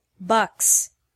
• Listen to the pronunciation